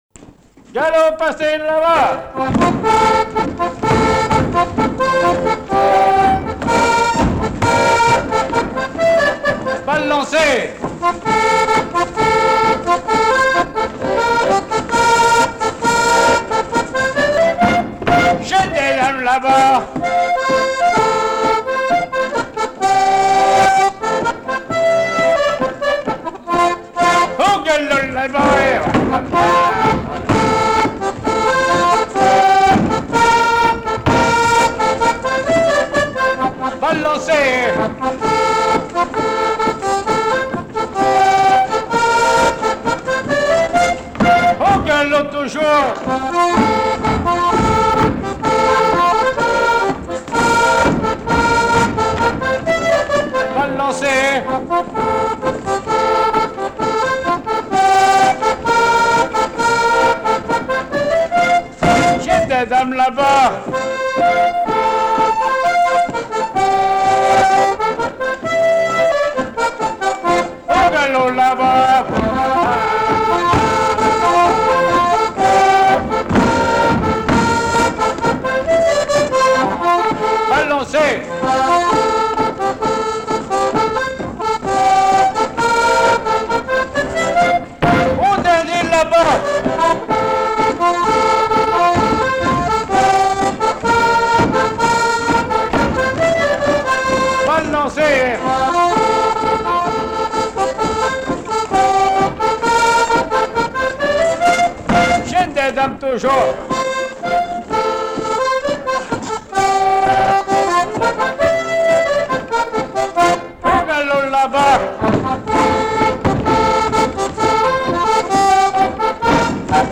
Résumé instrumental
danse : quadrille : galop
Répertoire du musicien sur accordéon chromatique